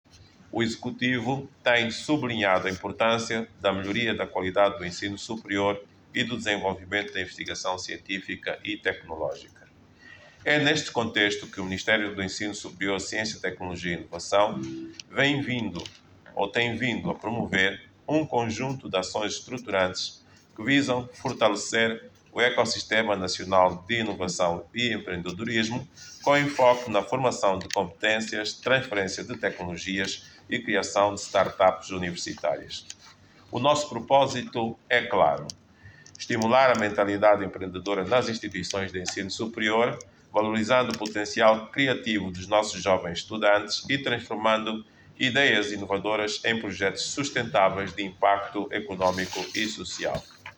O governante falava por ocasião da abertura do Workshop Nacional sobre Empreendedorismo, que decorre sob o lema “Fomentar o espírito empreendedor e dar vida a projectos inovadores nas IES”.